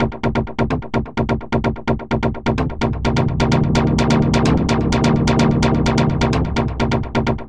Stab 128-BPM D#.wav